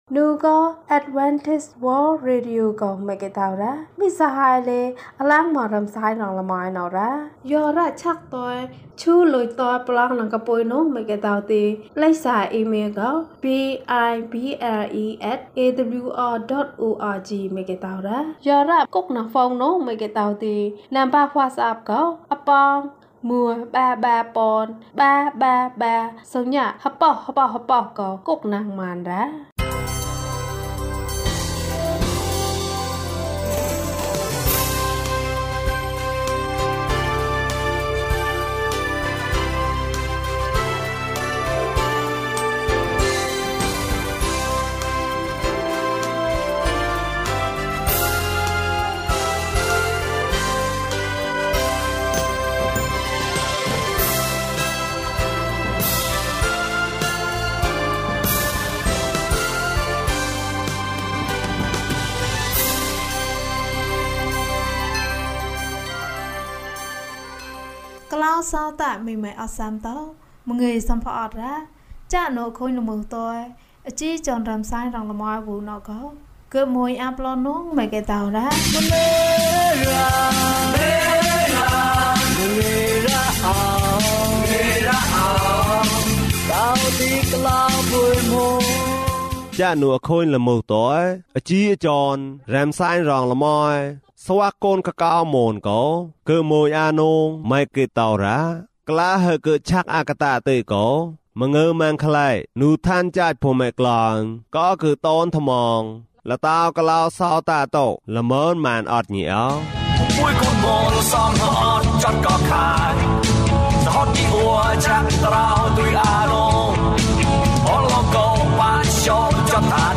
ခရစ်တော်ထံသို့ ခြေလှမ်း ၁၃။ ကျန်းမာခြင်းအကြောင်းအရာ။ ဓမ္မသီချင်း။ တရားဒေသနာ။